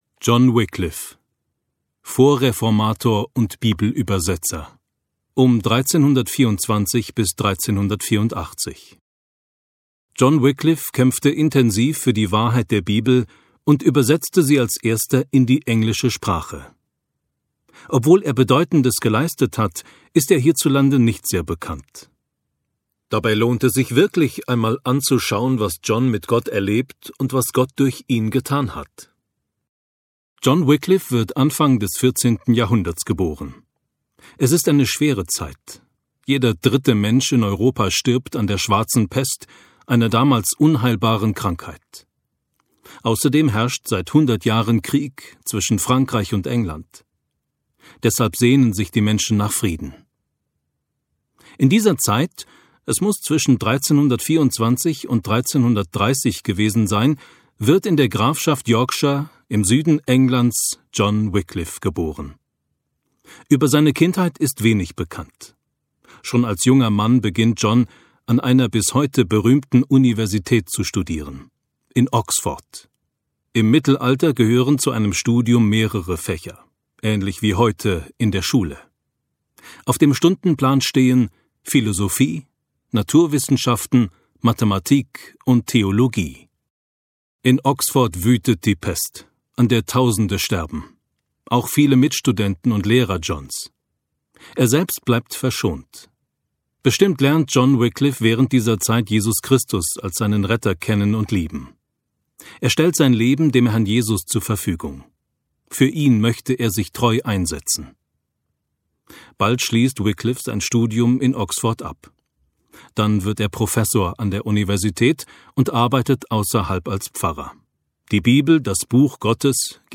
Glaube und Mut (Hörbuch, MP3-CD)